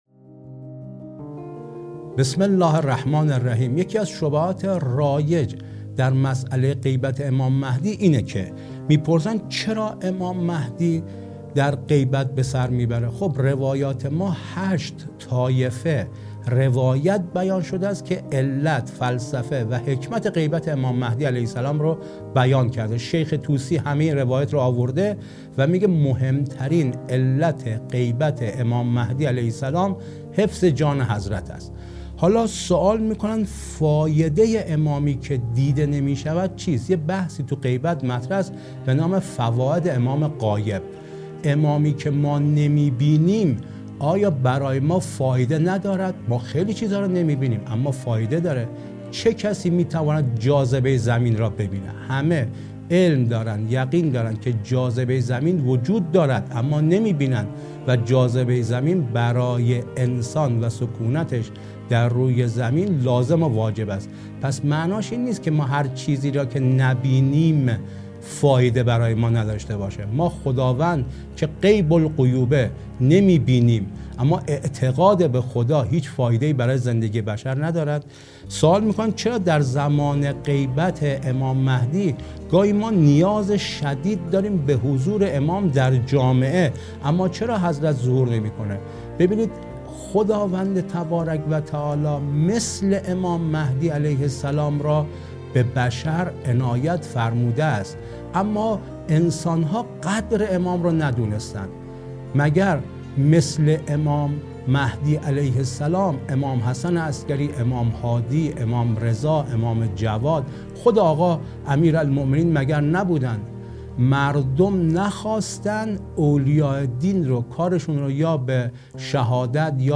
گفتگویی تخصصی